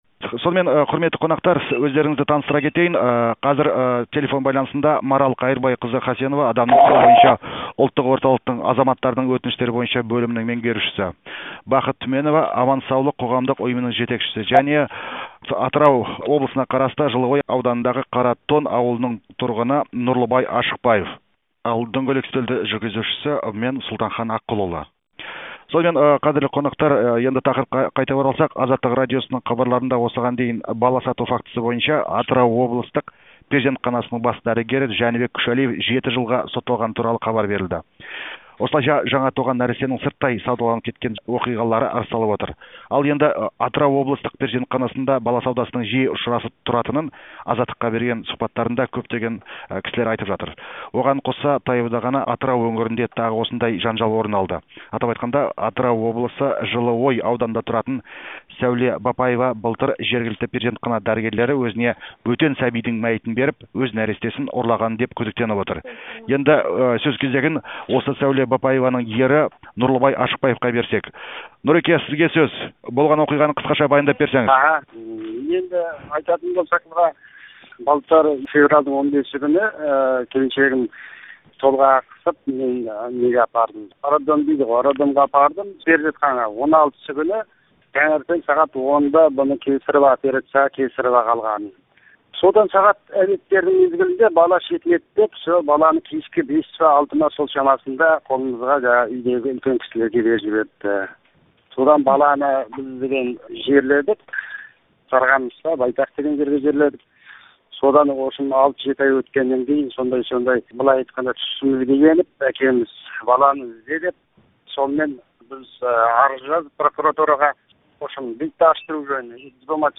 Сәби саудасына қатысты дөңгелек үстелді тыңдаңыз